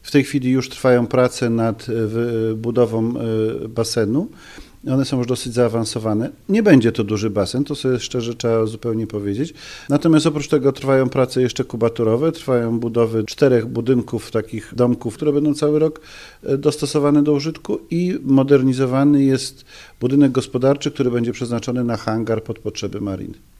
Mówi wójt Paweł Pisarek: